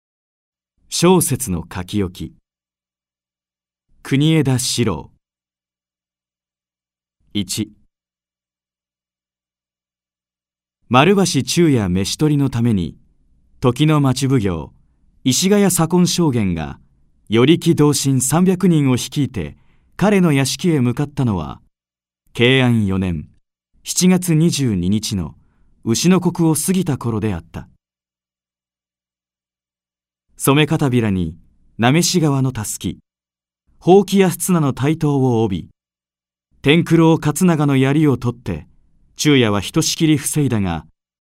朗読ＣＤ　朗読街道124「正雪の遺言・赤坂城の謀略」国枝史郎
朗読街道は作品の価値を損なうことなくノーカットで朗読しています。